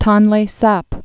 (tŏnlā săp, säp)